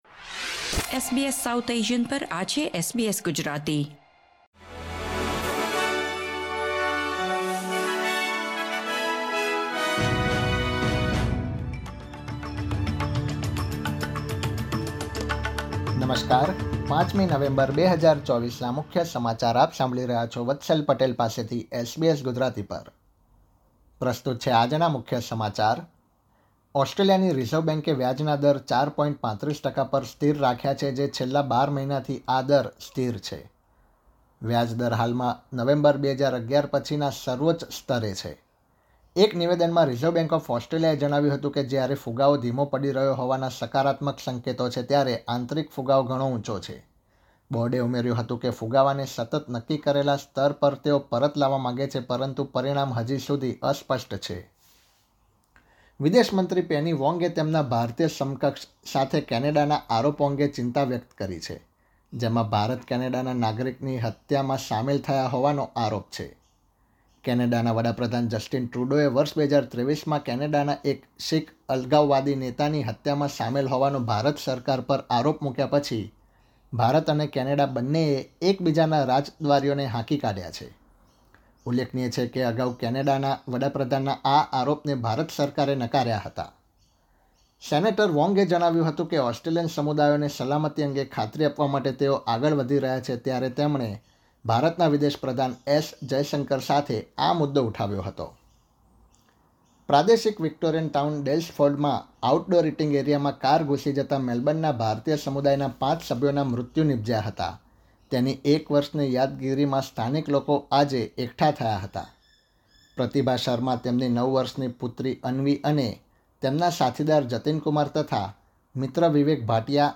SBS Gujarati News Bulletin 5 November 2024